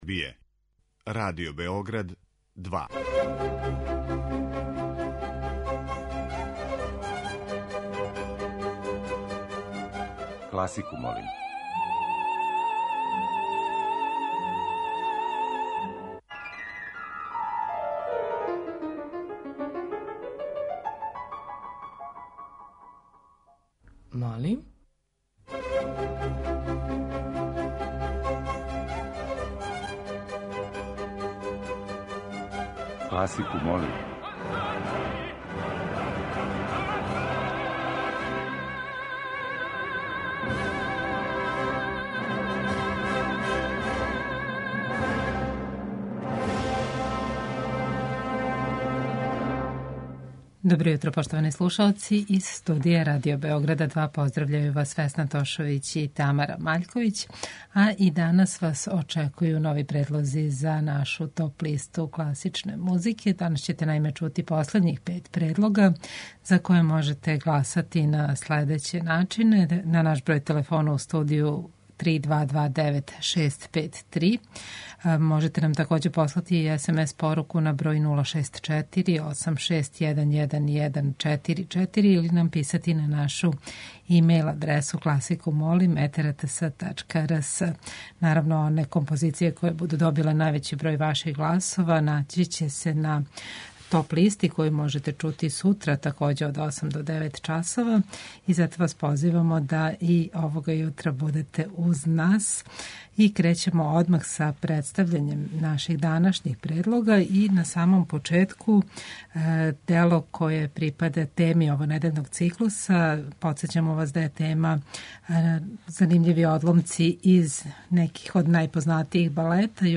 У оквиру теме овонедељног циклуса слушаћете одабране одломке из познатих балета.
Уживо вођена емисија, окренута широком кругу љубитеља музике, разноврсног је садржаја, који се огледа у подједнакој заступљености свих музичких стилова, епоха и жанрова.